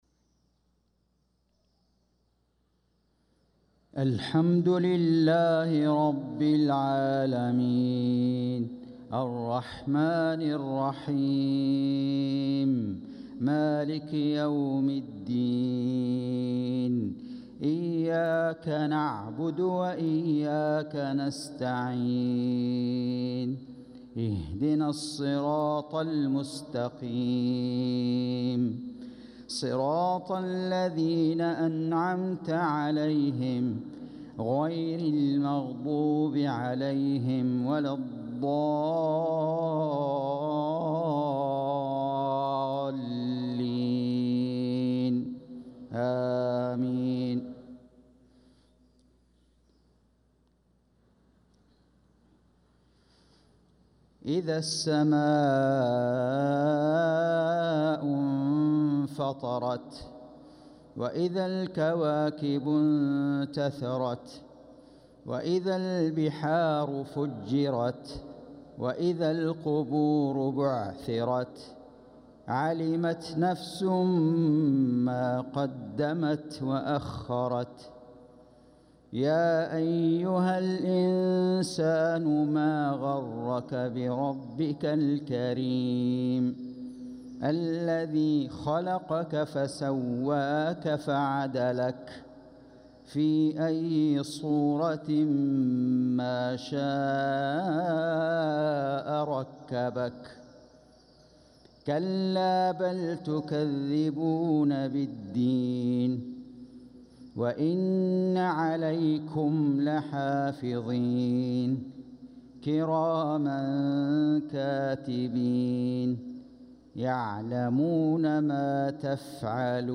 صلاة المغرب للقارئ فيصل غزاوي 12 ربيع الآخر 1446 هـ
تِلَاوَات الْحَرَمَيْن .